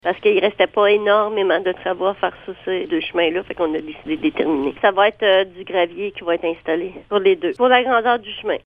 Voici les explications de la mairesse de Grand-Remous, Jocelyne Lyrette :